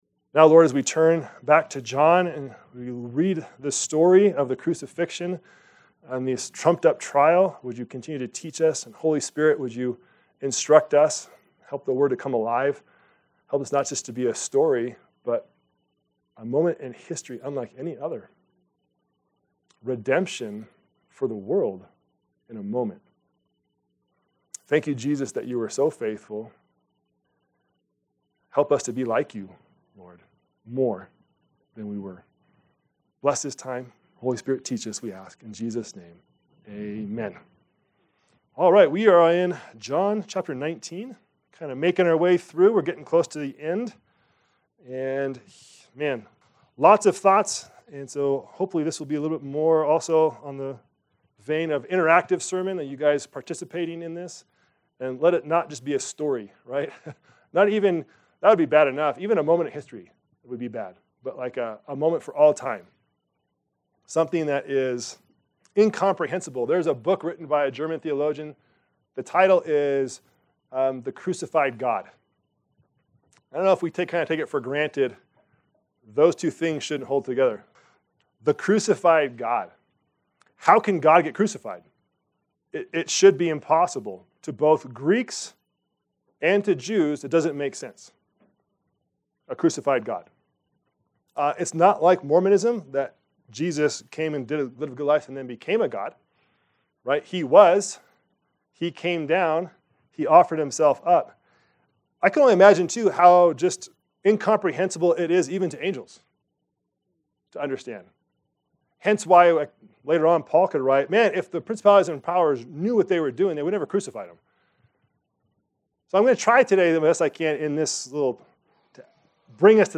Our Sermons